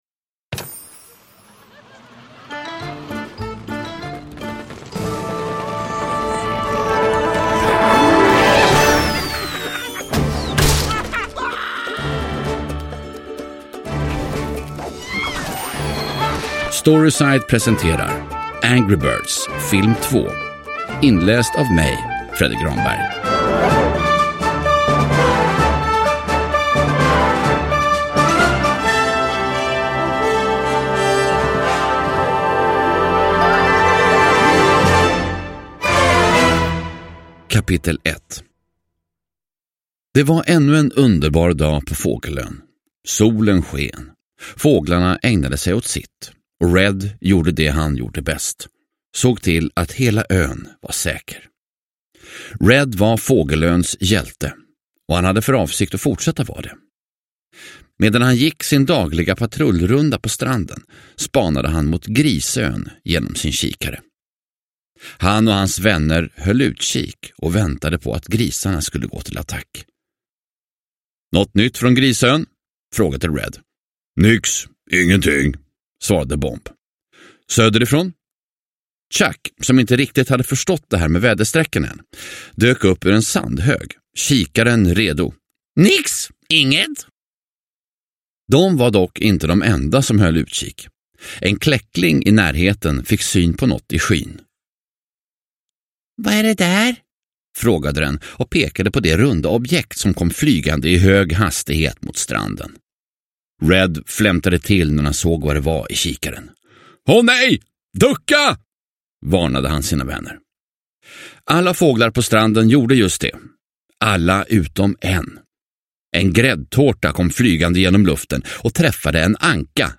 Angry birds Filmen 2 – Ljudbok – Laddas ner
Uppläsare: Fredde Granberg